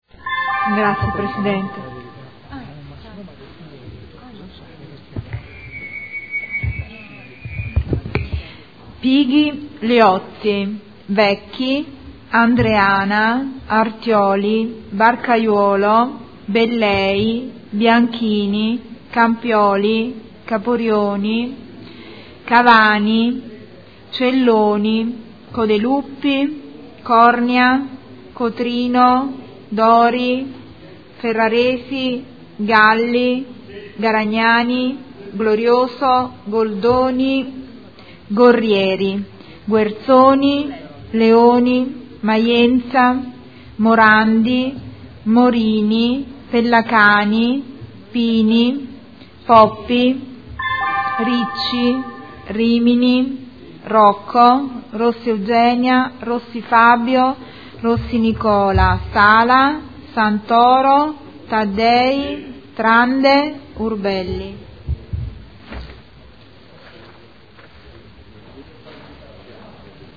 Segretario Generale — Sito Audio Consiglio Comunale
Seduta del 16 gennaio. Appello